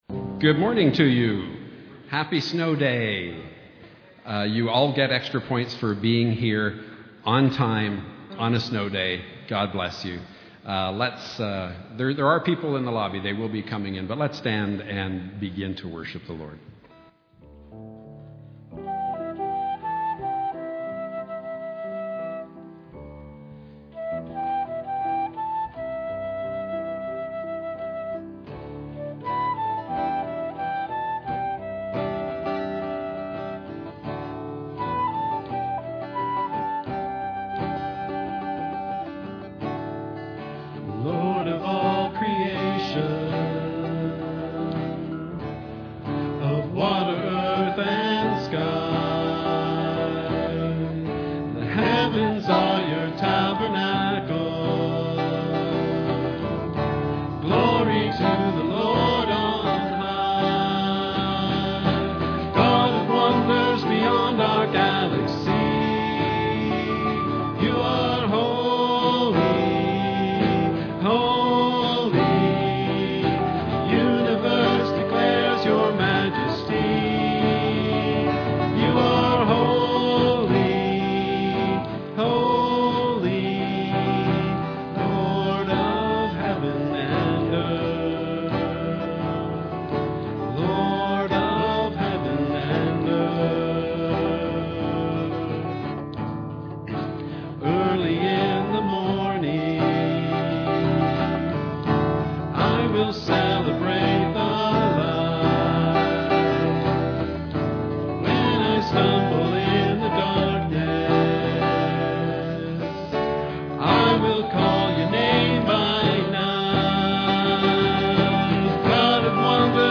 Message
Note Contemporary recording is incomplete.